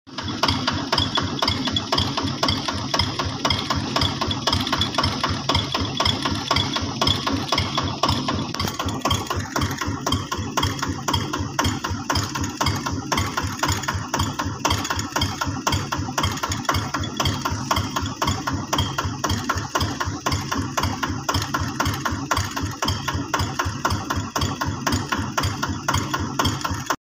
diesel kubota rd 85 1 sound effects free download